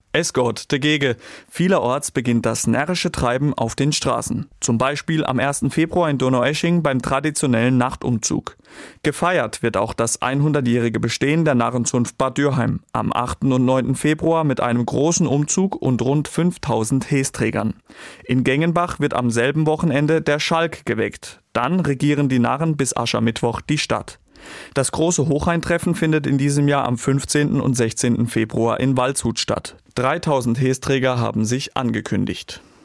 SWR-Reporter